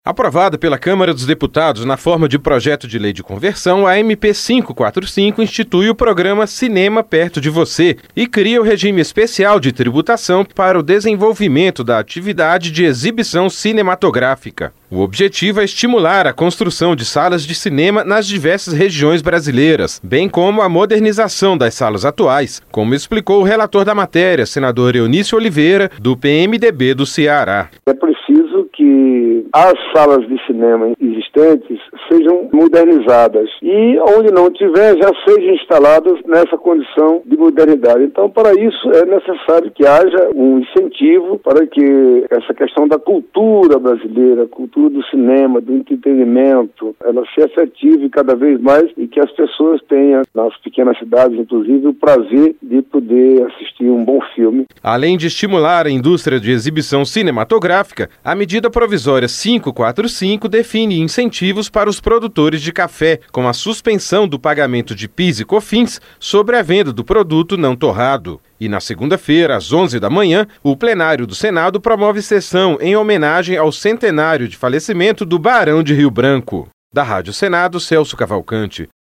O objetivo é estimular a construção de salas de cinema nas diferentes regiões brasileiras, e a modernização das salas atuais, como explicou o relator da matéria, senador Eunício Oliveira, do PMDB do Ceará.